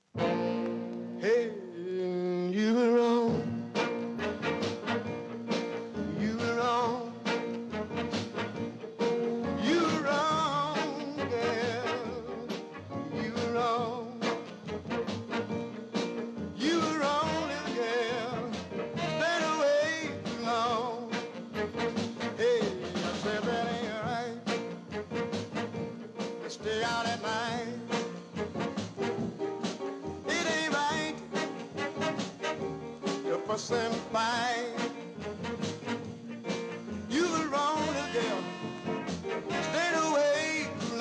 Mod & R&B & Jazz & Garage